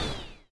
minecraft / sounds / mob / vex / hurt1.ogg
hurt1.ogg